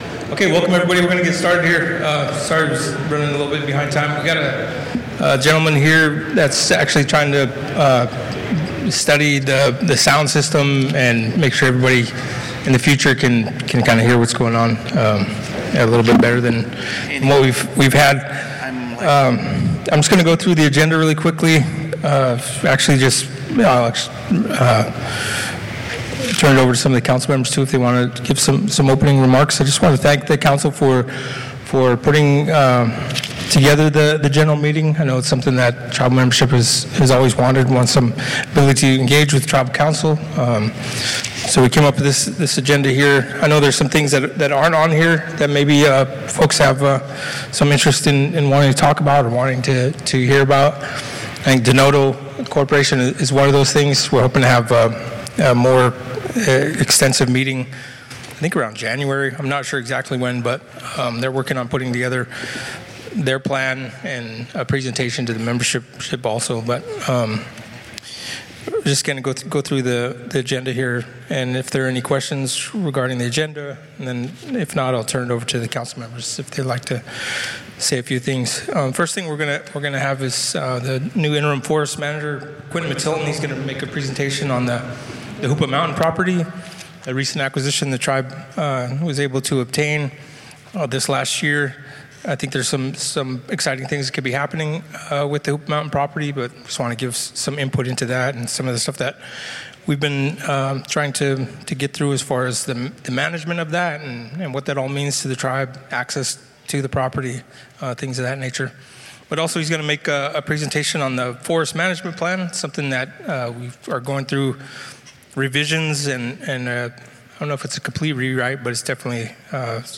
This is the audio recording of the Hoopa Valley Tribal General Meeting November 16, 2024 Hr. 1.